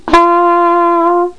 flugel12.mp3